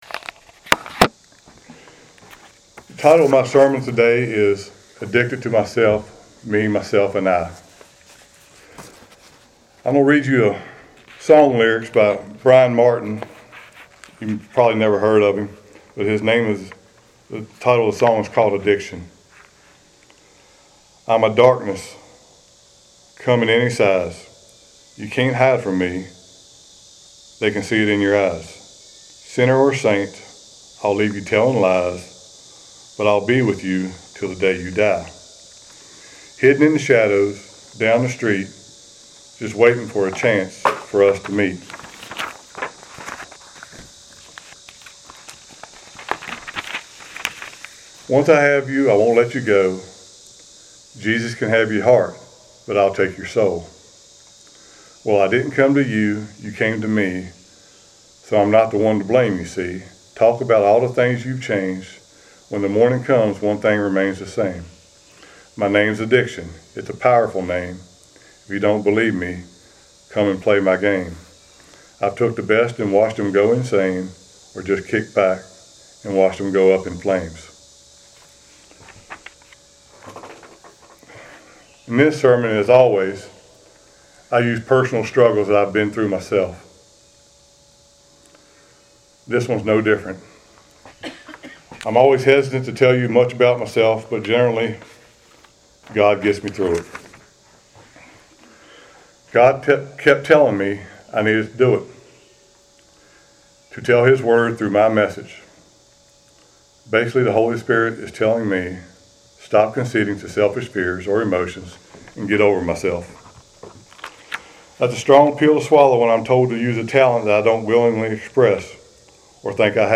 Passage: Ephesians 6:10-18 Service Type: Sunday Worship